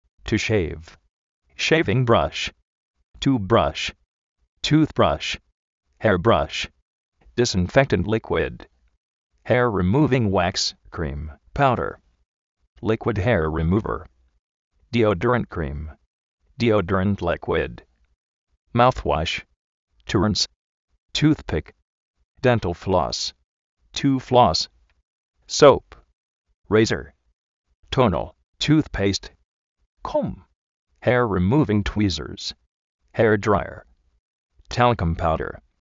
tu shéiv
shéivin brash
tuz-brásh
máuz-uósh
túz-péist